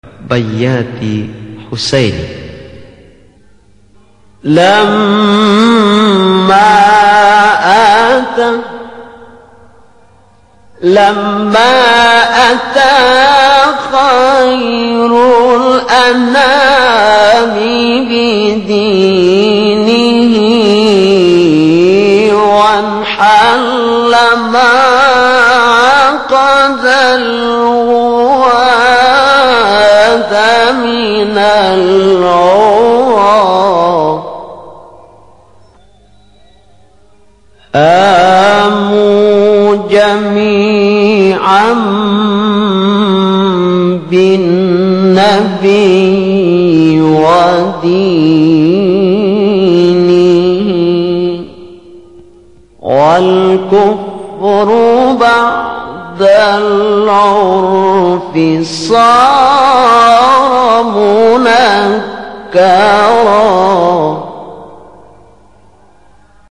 بیات-فرعی-حسینی-1.mp3